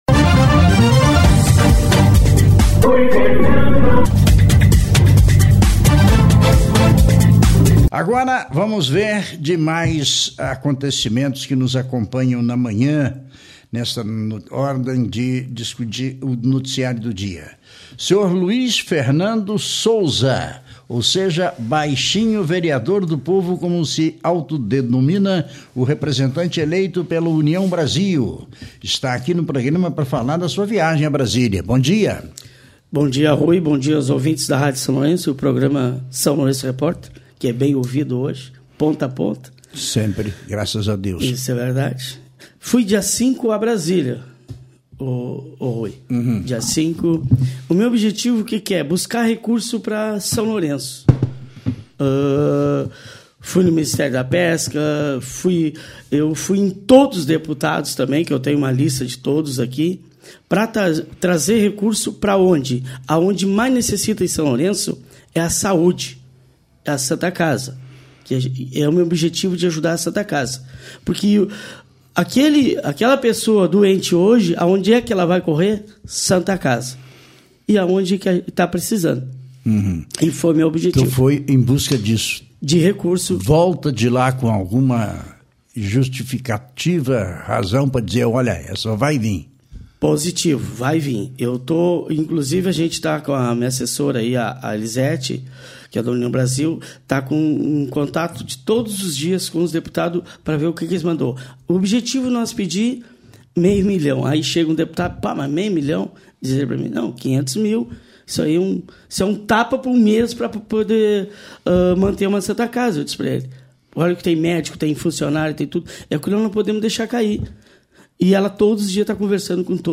Vereador Luís Fernando Souza (Az Baixinho), do União Brasil, esteve no SLR RÁDIO desta terça-feira (20), para falar sobre a recente ida à Brasília atrás de recursos para à Saúde Pública, em especial para a Santa Casa de Misericórdia de São Lourenço do Sul.
Na oportunidade, também comentou sobre o encontro com o Ministério da Pesca para debater a ampliação na pesca da tainha na Lagoa dos Patos – escute a entrevista:
ENTREVISTA-20.5-AZ-Baixinho-Vereador.mp3